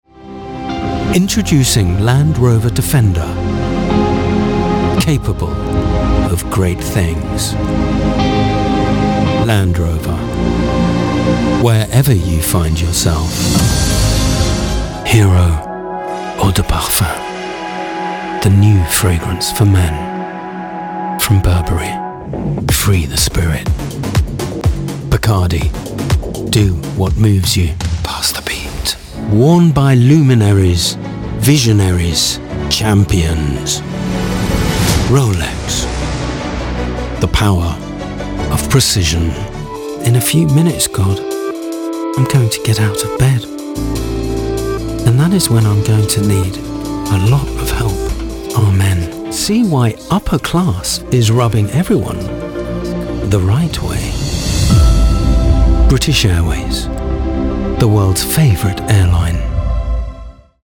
Styles: Friendly/Upbeat, Corporate, Sensitive/Reassuring, Natural/Unaffected, Engaging, Sophisticated, /Posh Sexy.
Accents: Neutral, RP, London, Cockney, Posh, French
Commercials Reel 60sec – Land Rover, Burberry, Cologne, Bacardi, Rolex, ‘Dear God’, British Airways.
Sound Proofed Studio